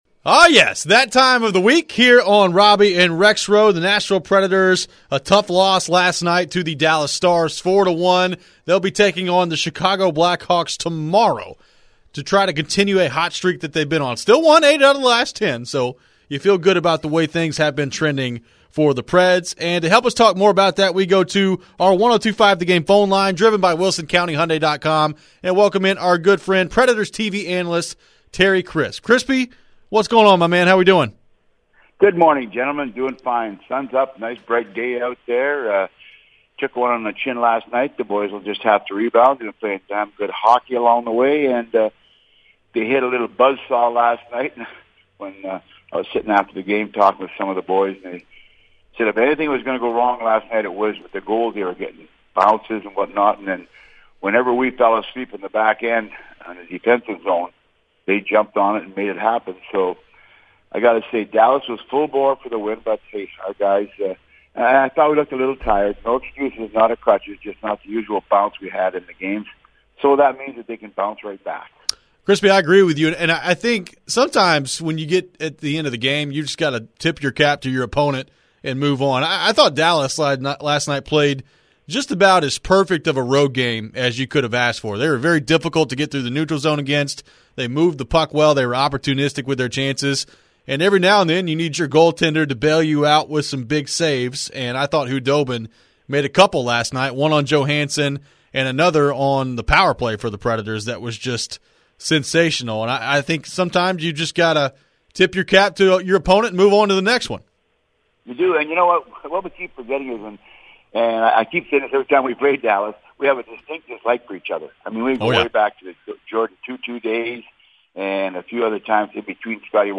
Preds TV Analyst Terry Crisp joined the show to discuss the Preds' disappointing loss and the end of their winning streak, evaluate some of the young players and more!